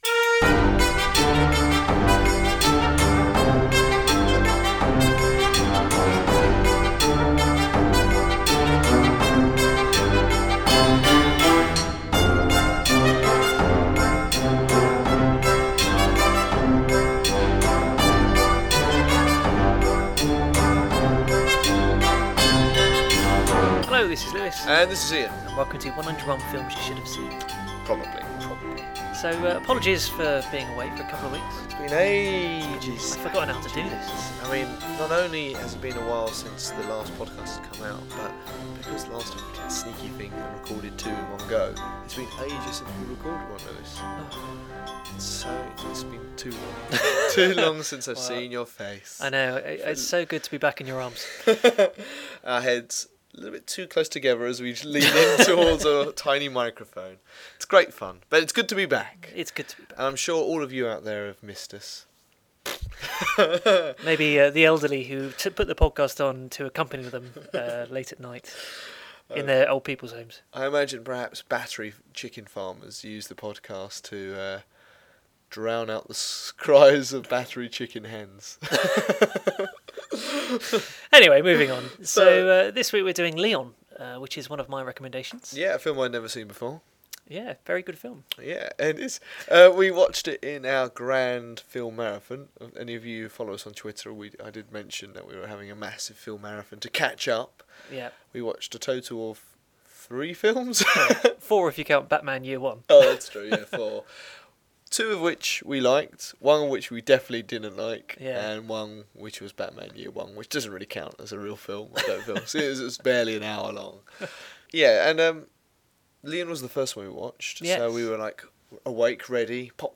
Then again, the ukulele challenge this week is an absolute shocker, so there are definitely at least some periods of low quality, but you can always fast forward through those I suppose… ANYWAY, this week we discuss Leon, the slightly creepy tale of a hitman and his 12-year-old ward, with added Gary Oldman action.